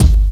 Boom-Bap Kick 68.wav